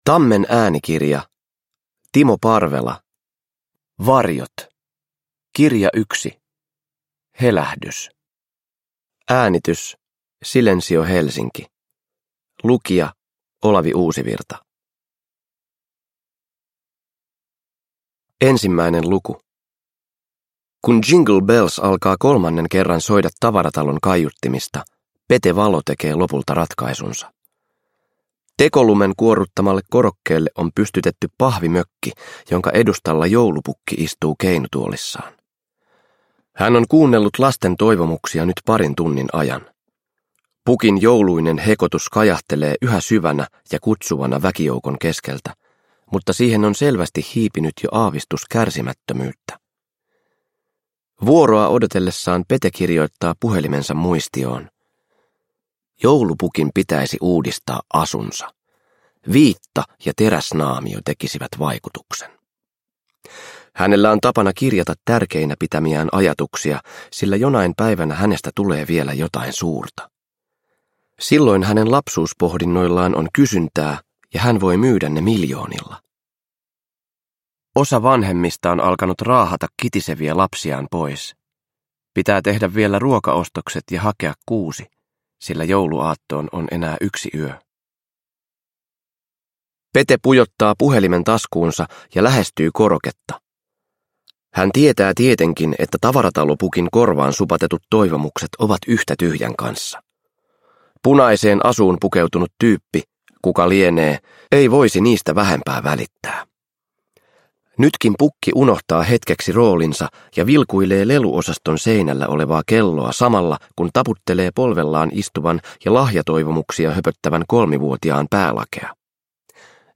Varjot 1. Helähdys – Ljudbok – Laddas ner
Uppläsare: Olavi Uusivirta